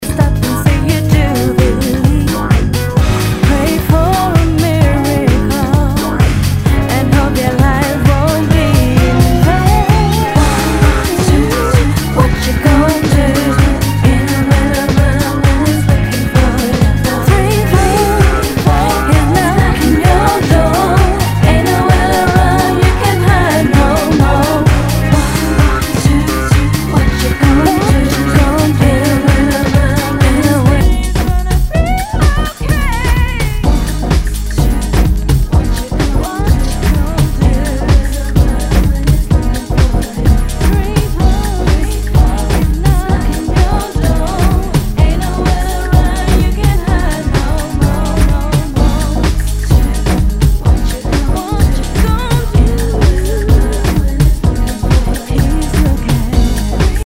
HOUSE/TECHNO/ELECTRO
ナイス！ヴォーカル・ハウス・ミックス！